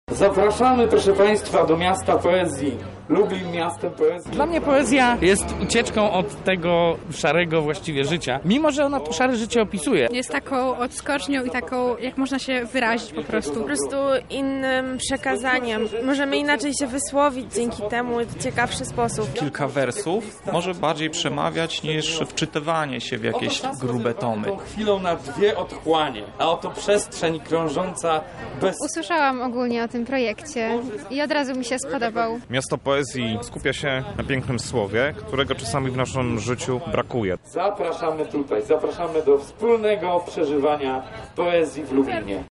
Czytanie wierszy, spotkania autorskie i spektakle teatralne – to tylko niektóre z nich. A jakie wrażeniach z pierwszego dnia imprezy mają jego uczestnicy?